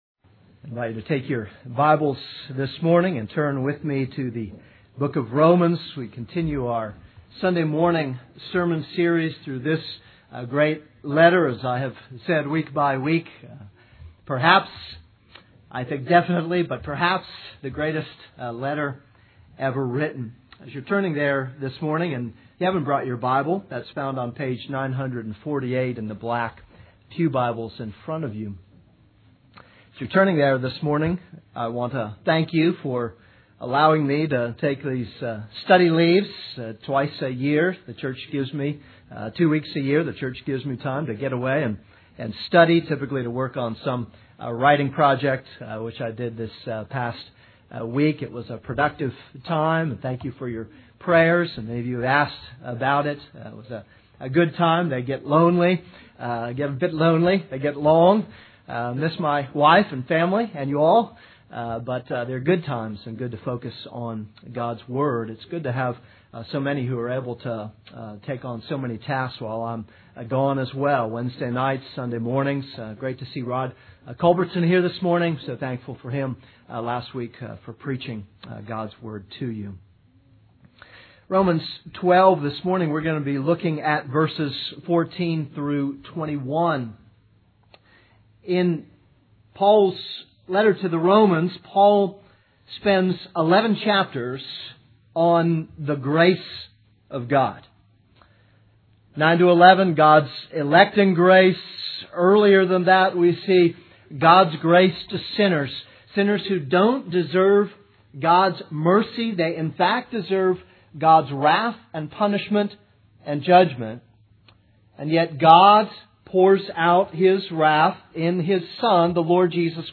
This is a sermon on Romans 2:14-21.